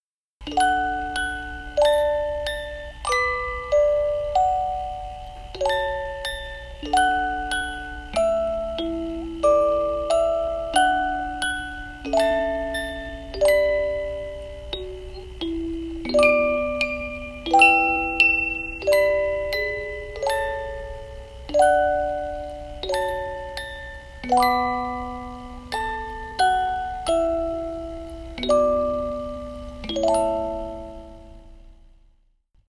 30弁オルゴールは、30音で構成される日本製オルゴールです。
1台１台が職人による手作りで製造される上質な音色は、聴く人の心に心地よく響きます。
搭載メカタイプ 30弁タイプ